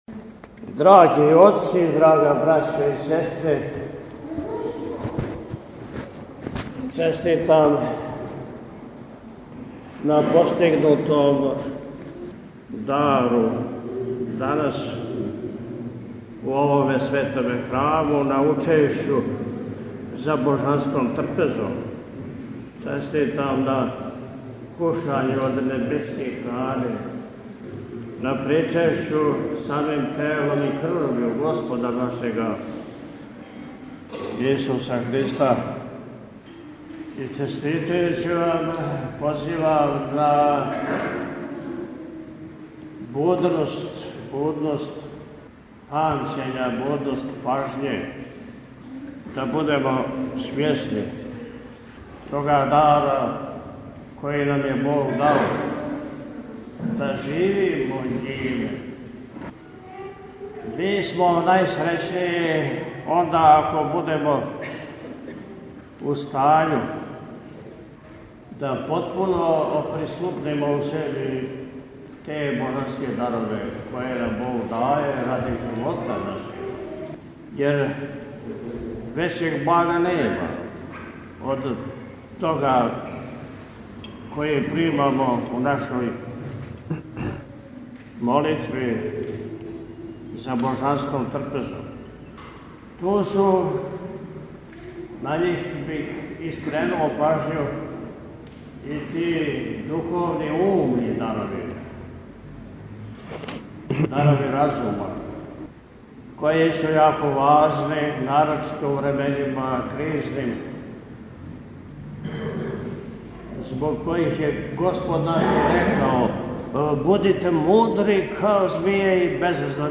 Недеља у храму Васкрсења Христовог у Прибоју - Eпархија Милешевска
PB-Ned-Beseda.mp3